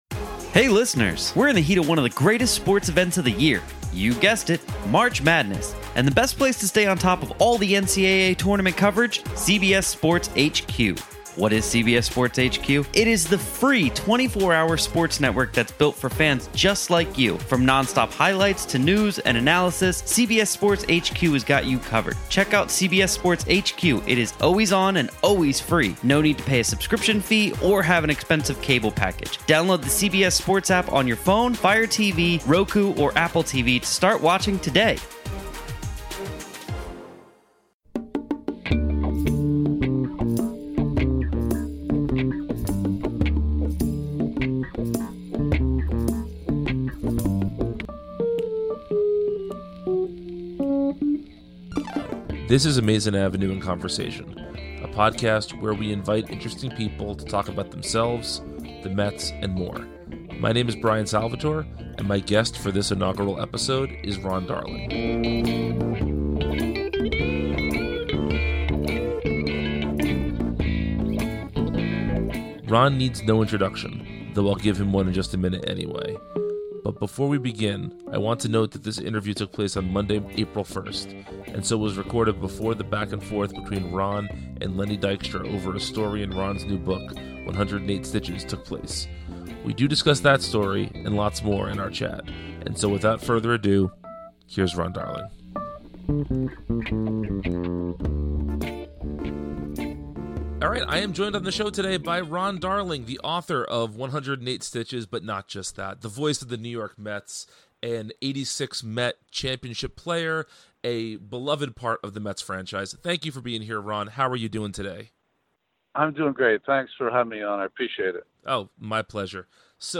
Our first guest is Ron Darling.